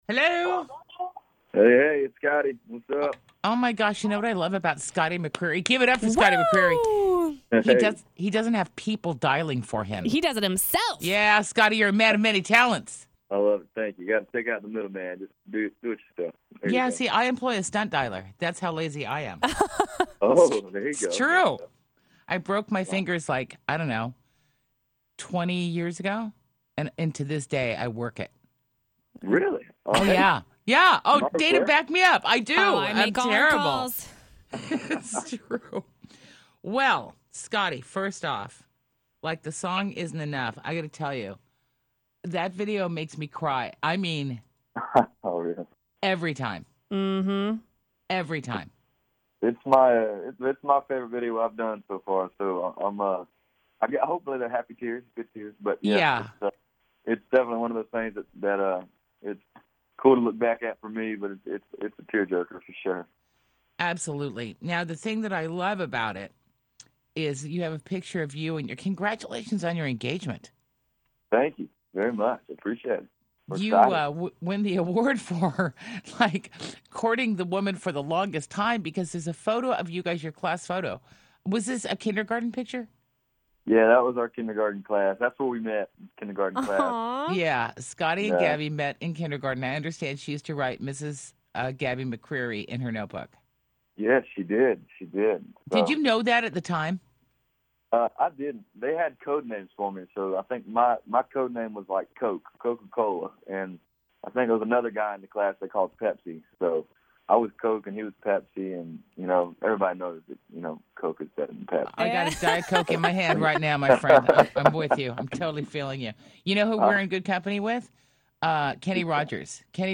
Here’s the full interview with Scotty McCreery!